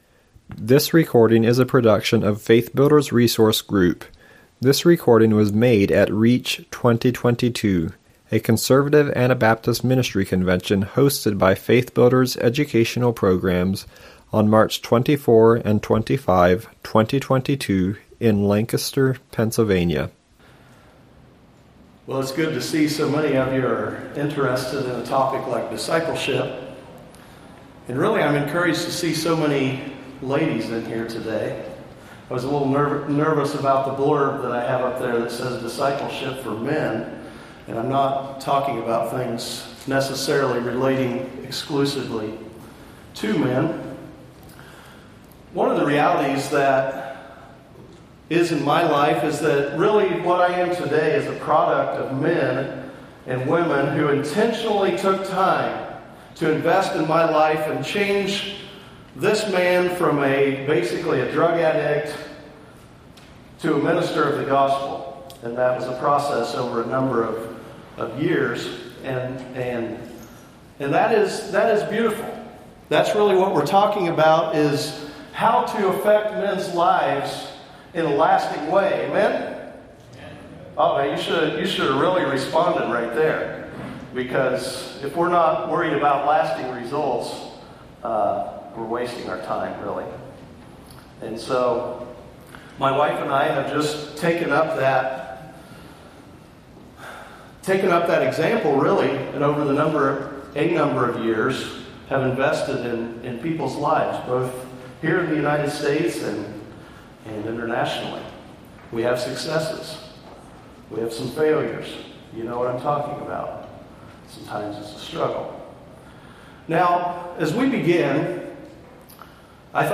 The world around us is rapidly changing. This session takes a look at how to endure in discipleship of men for lasting results, beginning in the church and reaching to the lost.